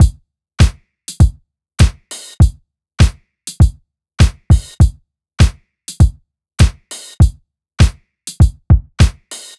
Unison Funk - 5 - 100bpm.wav